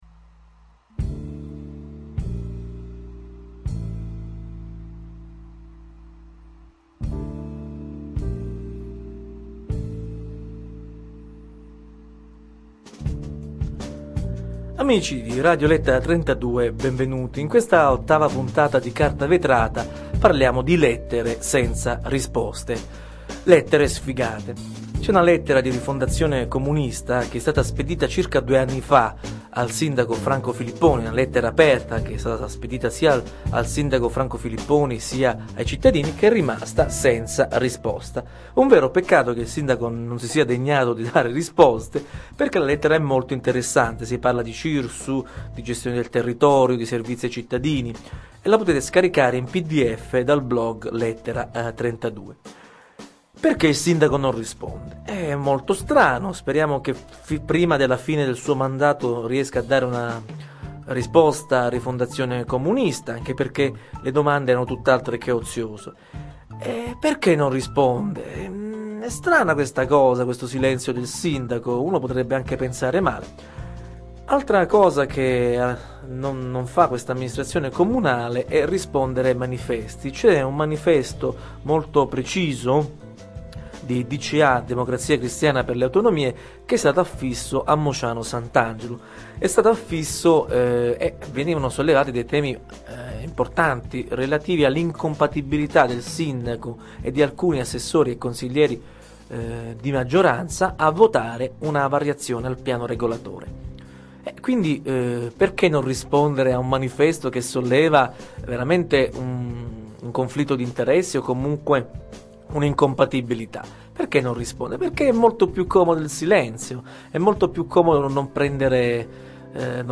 La puntata numero otto del programma radiofonico satirico "Carta Vetrata", è dedicata alle lettere senza risposta , spedite all'amministrazione comunale e rimaste senza replica.